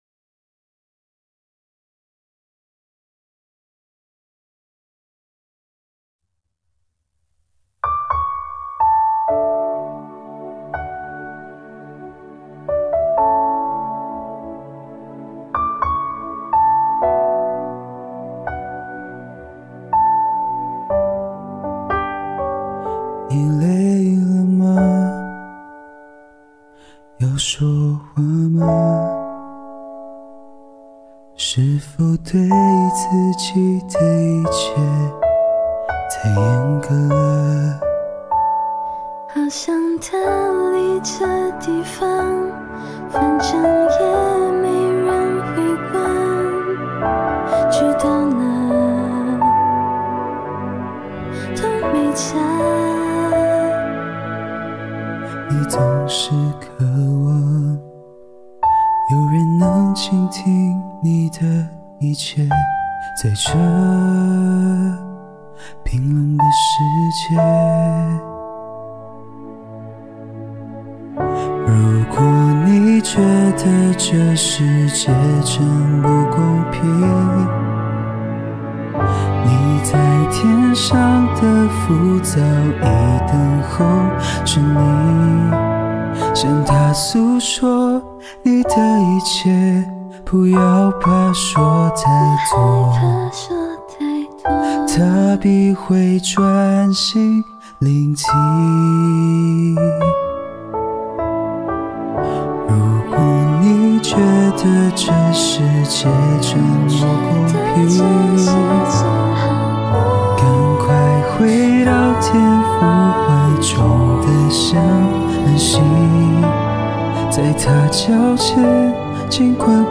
钢琴
弦乐